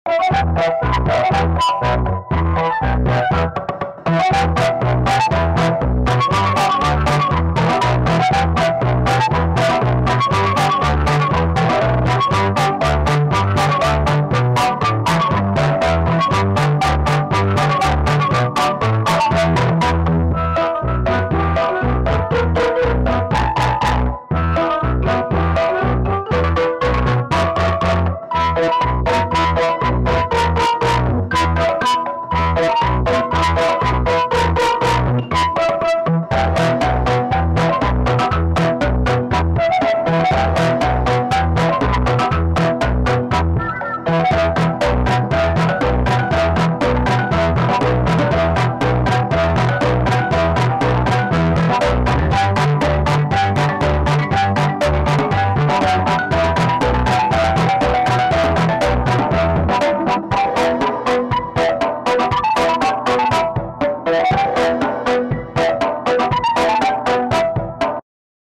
it’s low quality